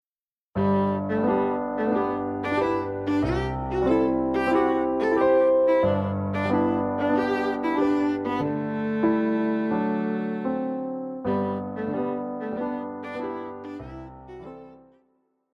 Classical
Viola
Piano
Solo with accompaniment